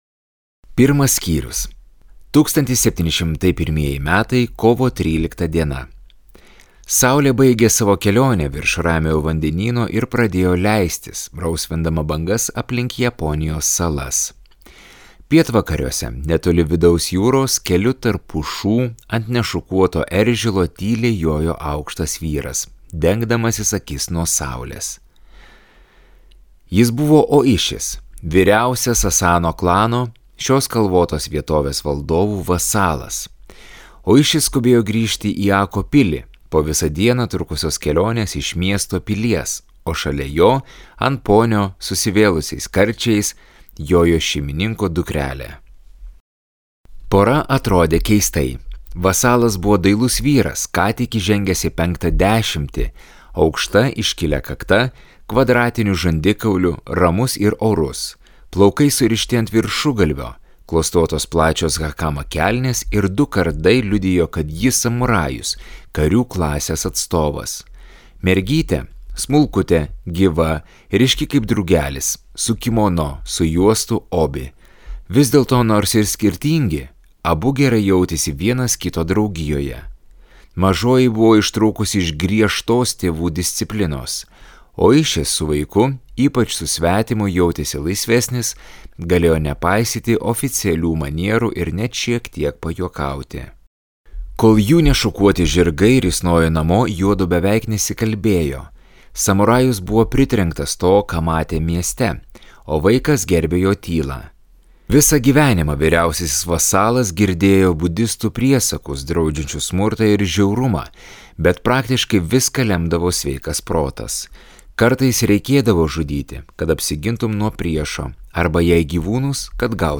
47 roninai | Audioknygos | baltos lankos